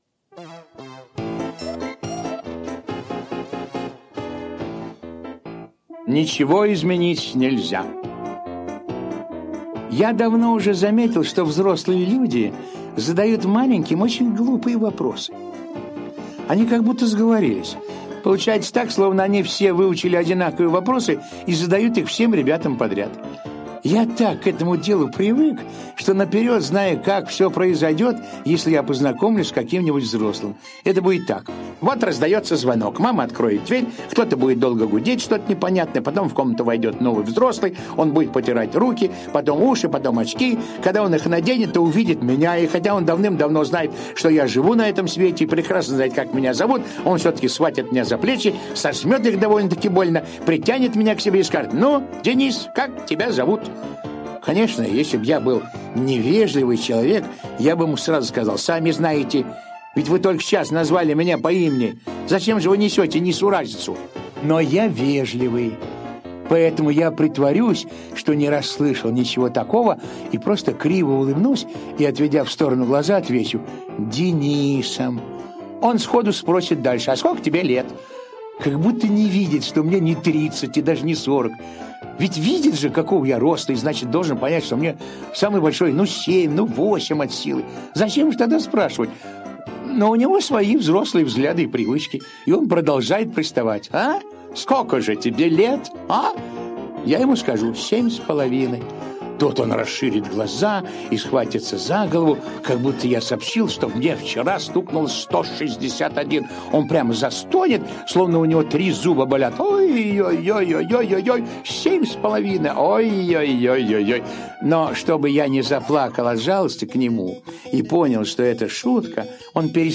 Аудиорассказ «Ничего изменить нельзя»